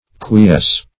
quiesce.mp3